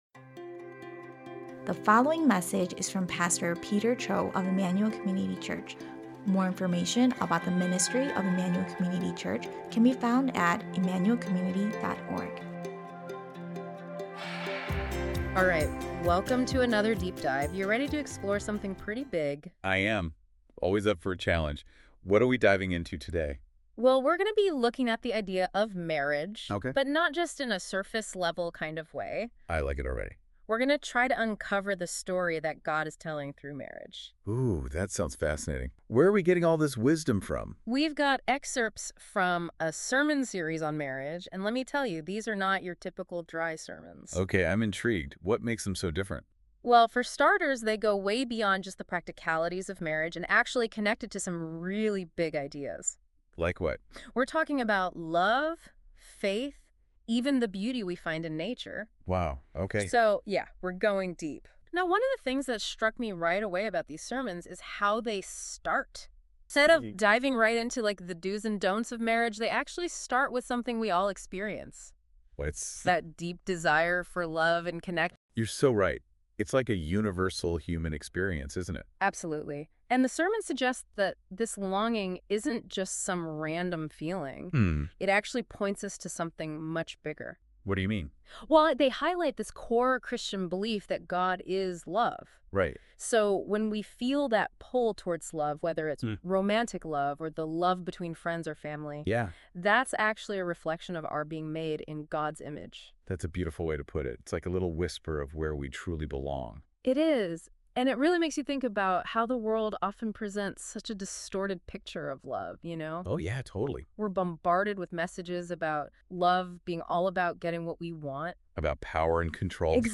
It was created by an AI powered tool by Google called NotebookLM. While not perfect, this podcast not only shares many of the big ideas introduced in the messages, it also interacts with it in an easy-to-listen format.